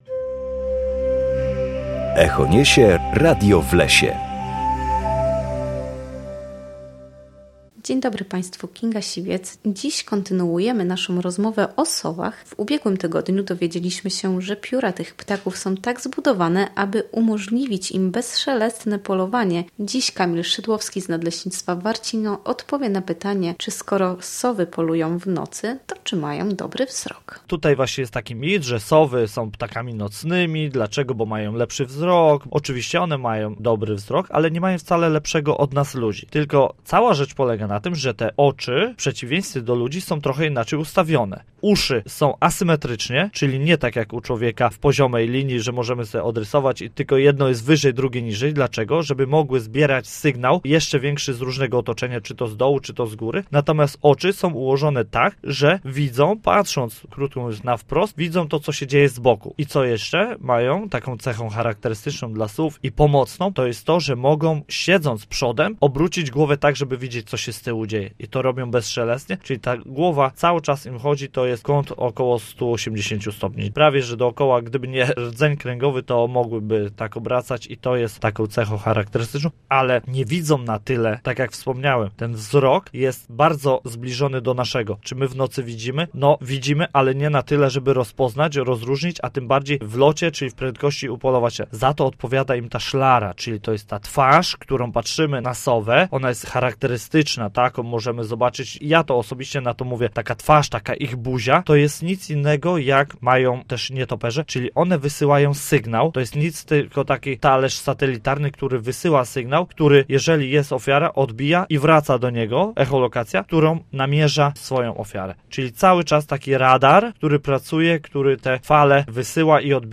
W każdą środę o godzinie 7:20 na antenie Studia Słupsk rozmawiamy o naturze i sprawach z nią związanych.